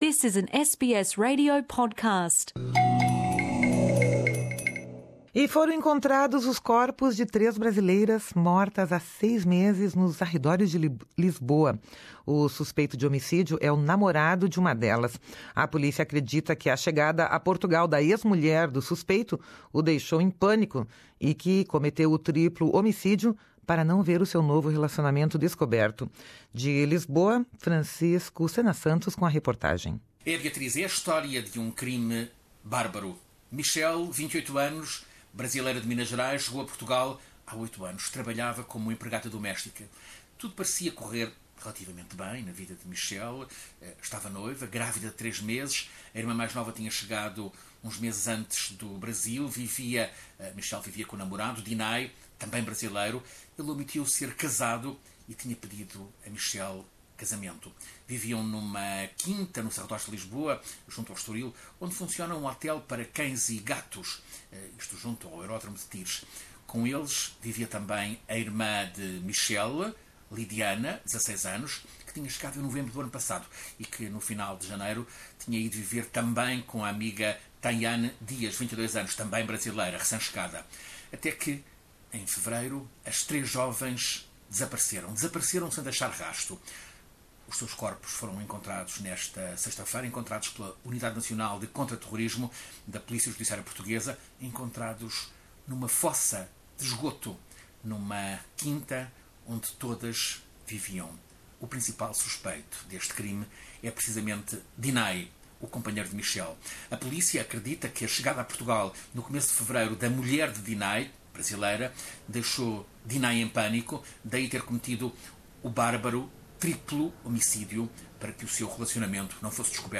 De Lisboa, a reportagem